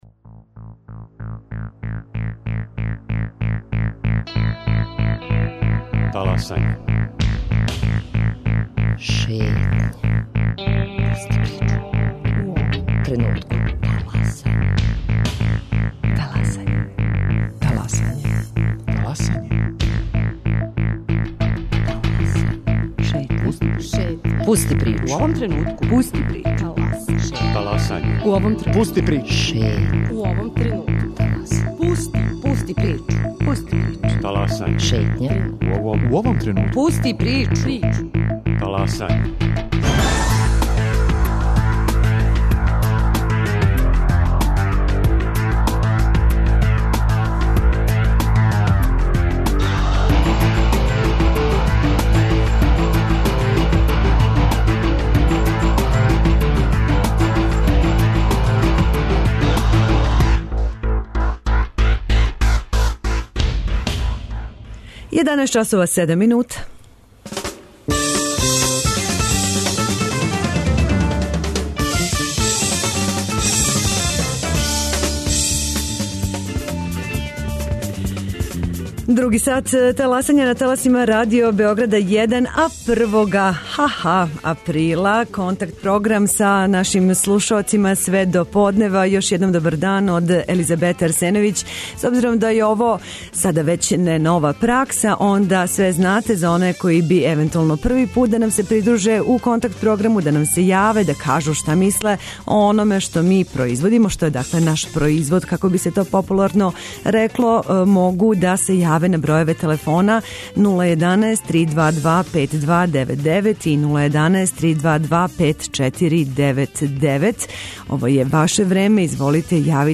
контакт - програм И Ваш верни слушалац